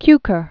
(kykər, -kôr, k-), George 1899-1983.